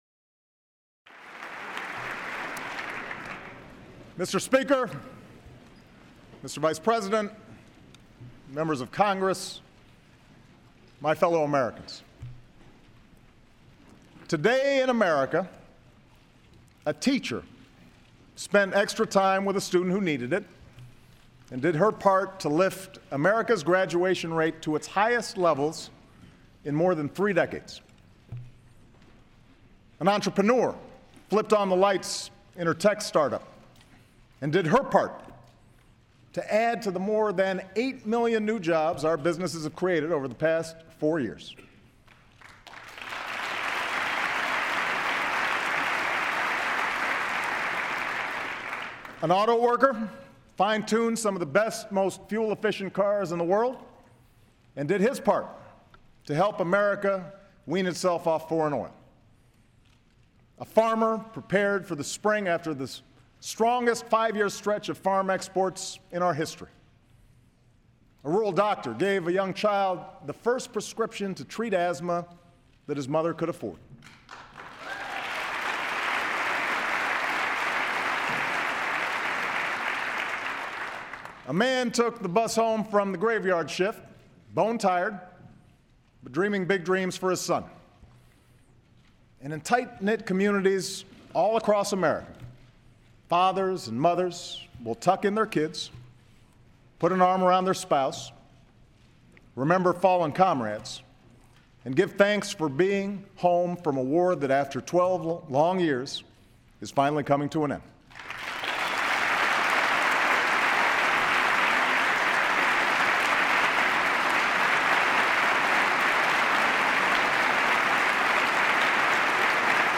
U.S. President Barack Obama delivers the annual State of the Union address before a joint session of Congress